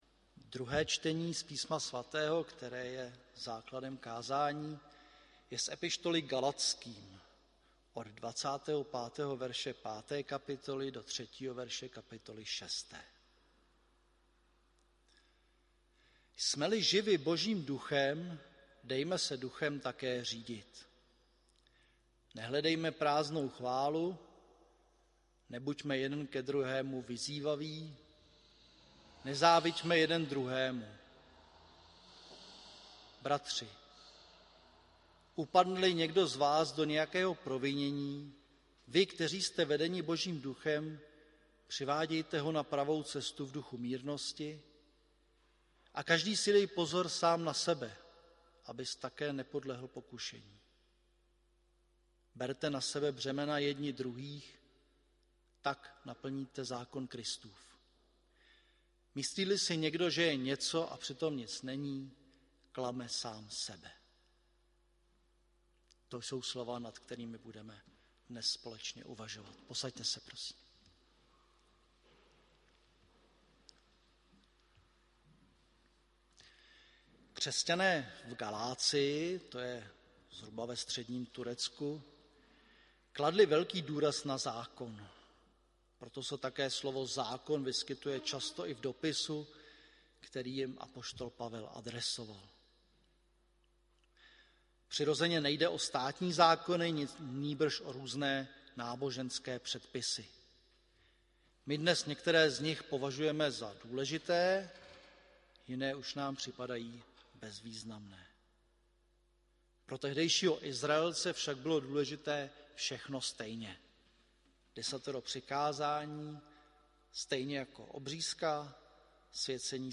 audio kázání